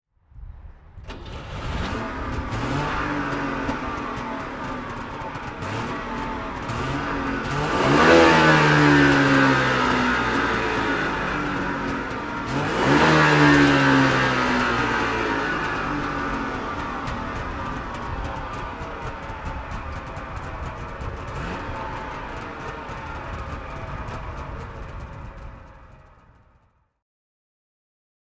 Auto Union 1000 S Coupé (1962) - Starten und Leerlauf